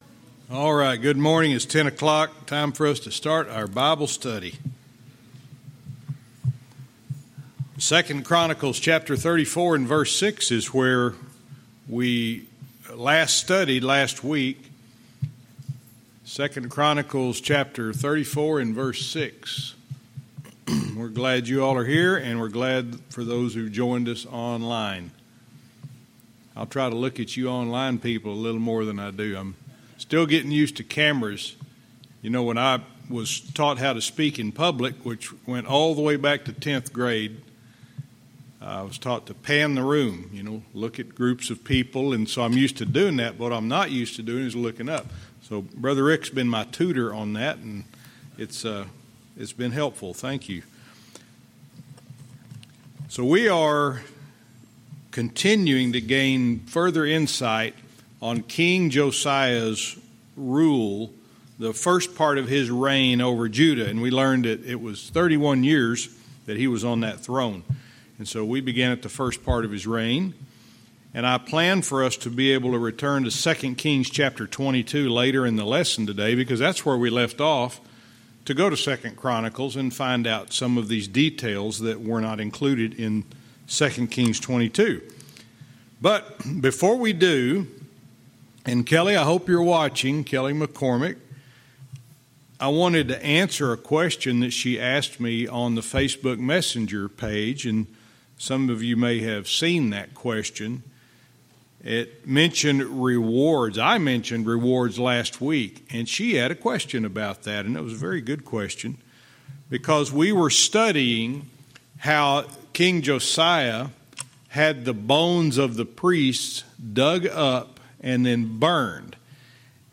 Verse by verse teaching - 2 Kings 22:3-4 & 2 Chronicles 34:7